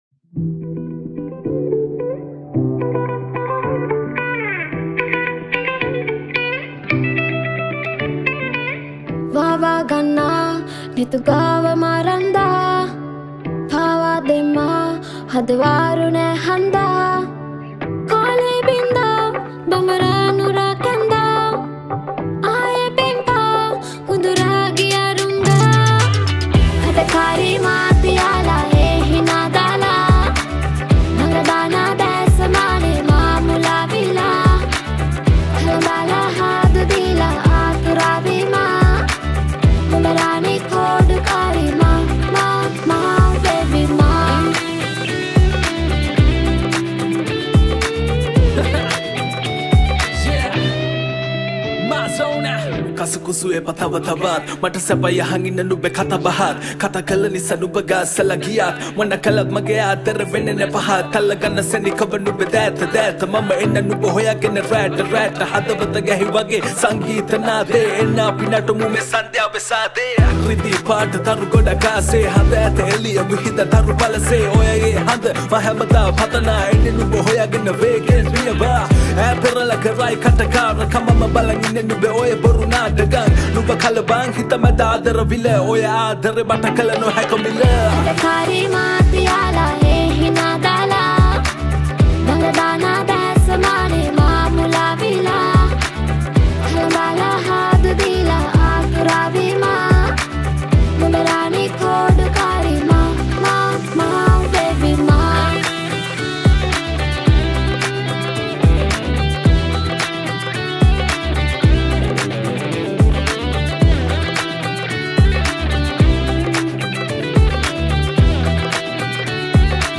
Releted Files Of Sinhala New Rap Mp3 Songs